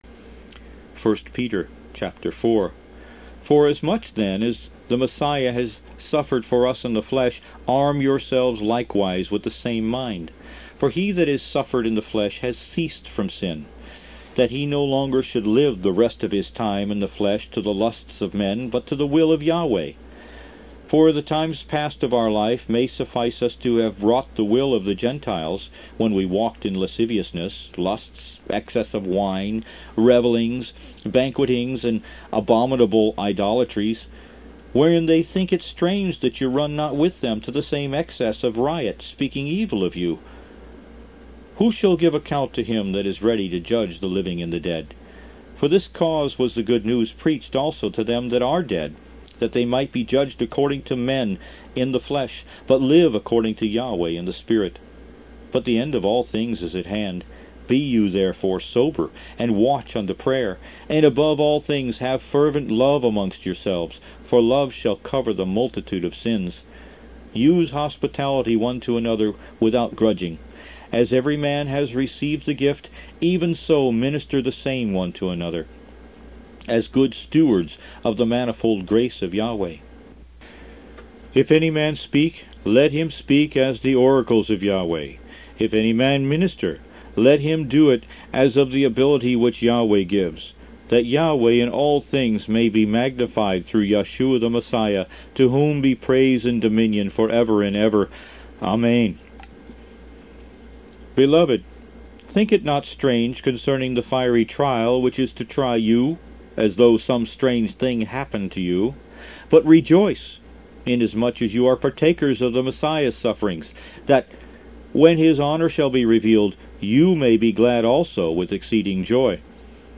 Root > BOOKS > Biblical (Books) > Audio Bibles > Messianic Bible - Audiobook > 21 The Book Of 1st Peter